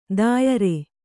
♪ dāyare